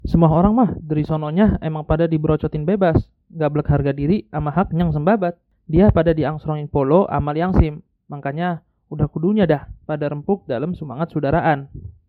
Sample text (Bekasi/Pebayuran dialect)
udhr_betawi-bekasi.mp3